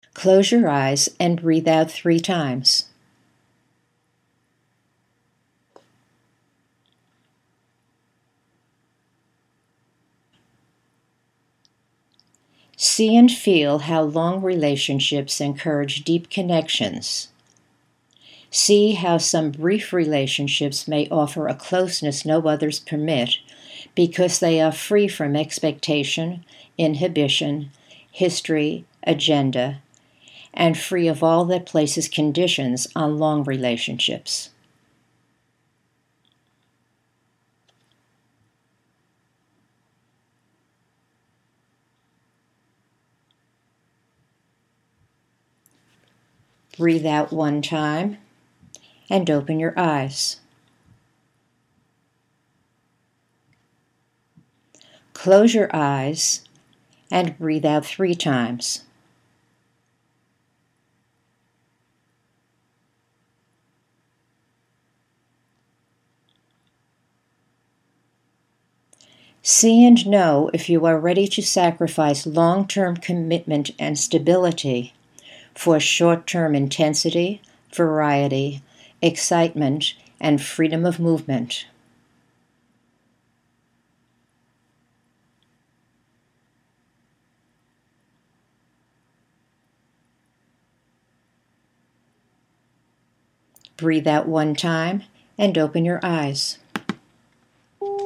At the end of each exercise you’ll find a quiet space of 8 seconds to focus on your images.
The simple version: until you hear the beep, there may be another instruction.